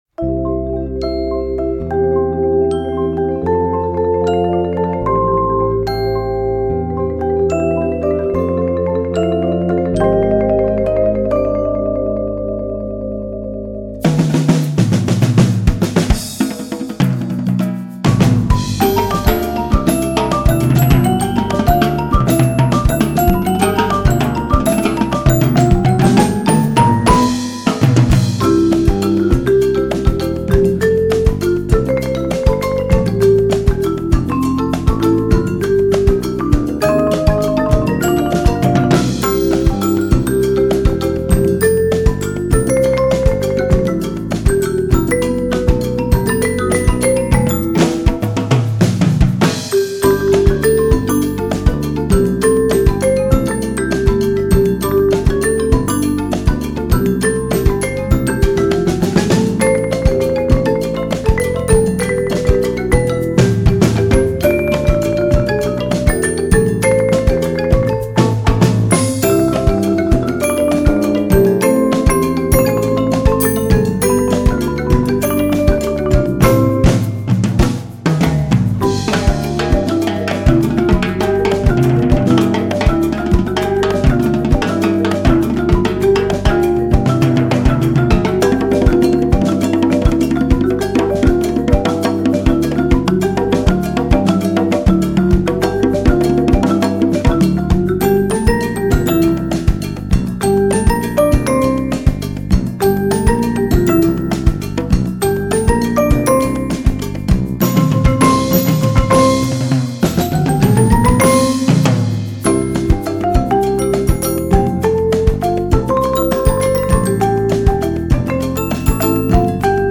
Voicing: 12 Percussion